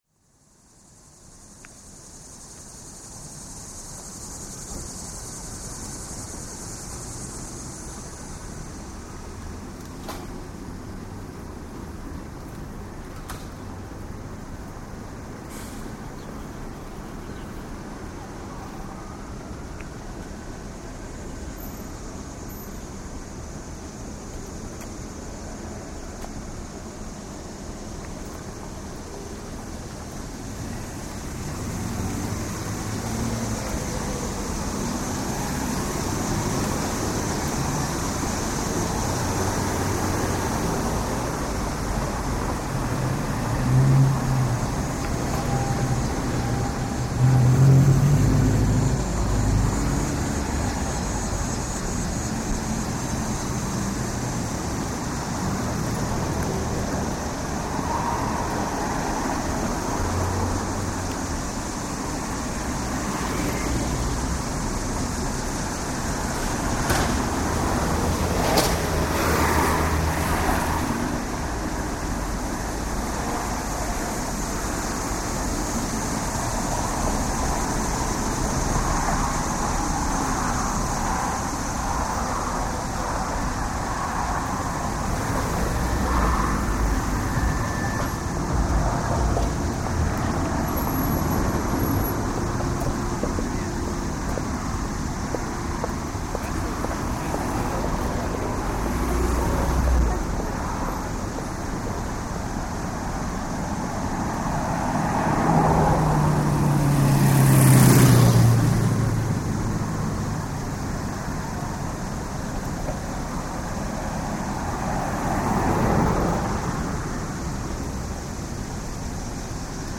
Soundwalk in Washington, D.C.
Sound recorded on walk between Macomb Street NW and the end of Woodley Place NW (walking over the Klingle Valley Bridge).